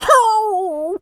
dog_hurt_whimper_howl_13.wav